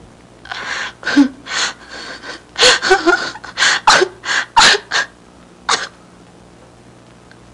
Crying (woman) Sound Effect
Download a high-quality crying (woman) sound effect.
crying-woman-1.mp3